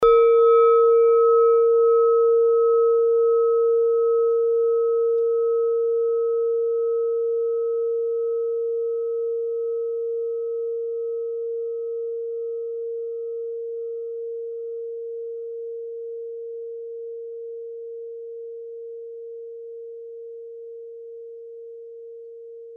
Klangschale Nepal Nr.34
Klangschale-Gewicht: 960g
Klangschale-Durchmesser: 14,7cm
(Ermittelt mit dem Filzklöppel)
klangschale-nepal-34.mp3